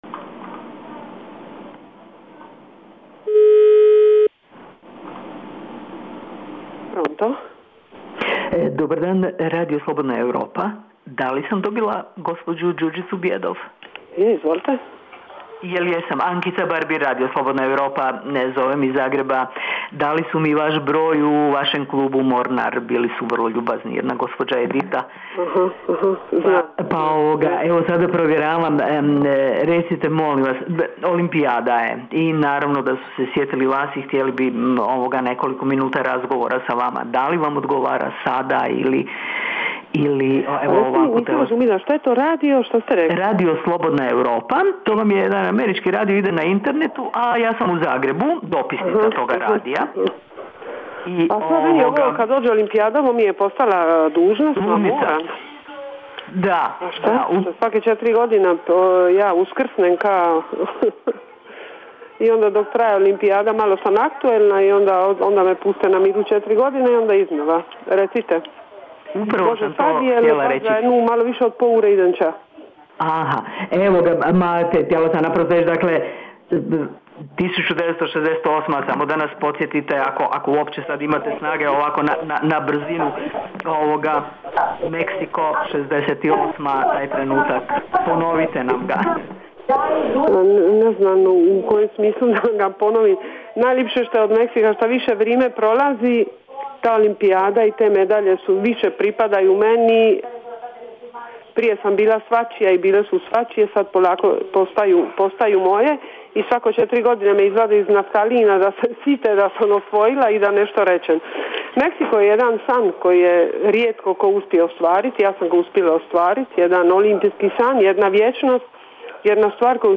Intervju sa Đurđicom Bjedov